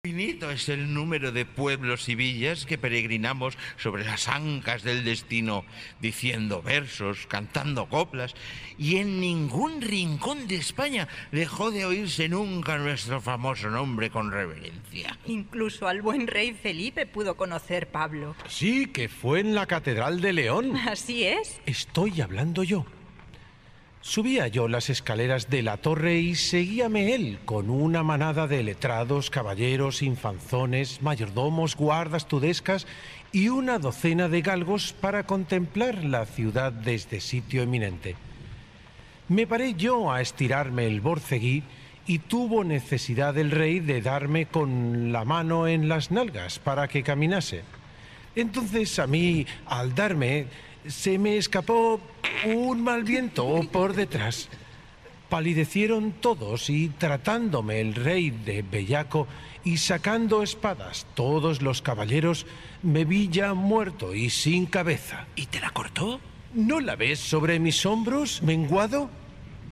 El jurado aprecia “la estupenda edición de sonido con unas voces muy compensadas y muy bien empastadas; también el buen ritmo de lectura, muy ajustado a lo que requiere el género”